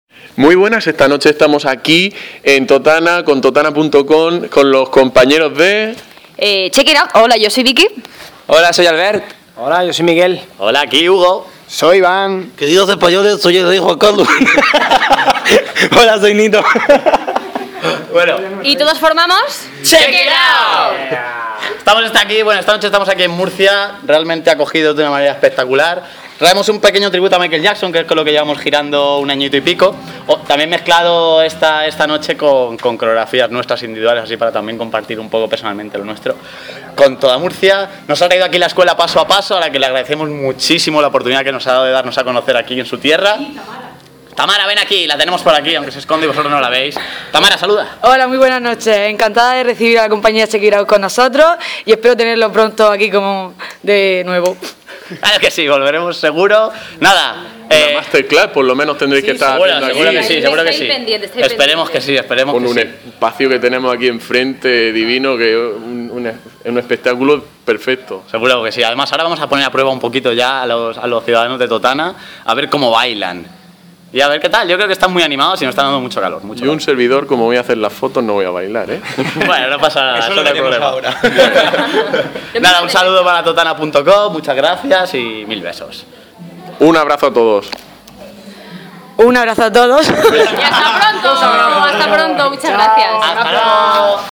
Festival de Baile "Check it out!"